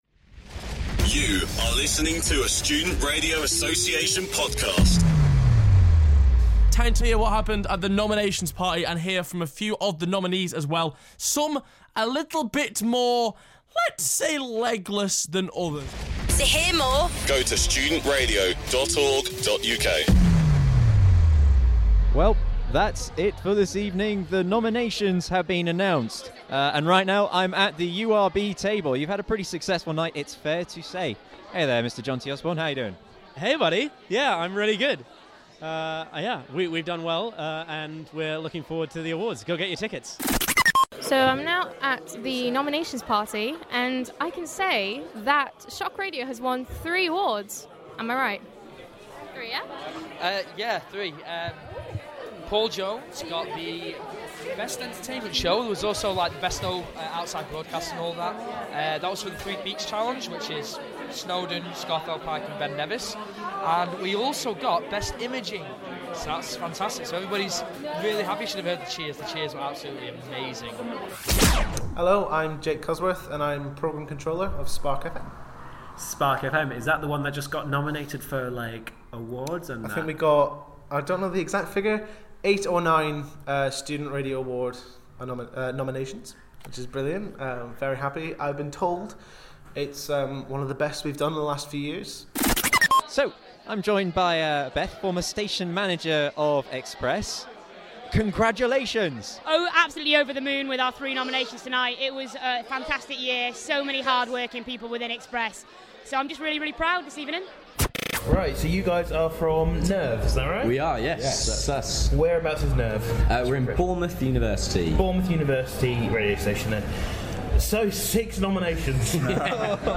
With only one week to go until the event itself, hear all the reactions from the night here!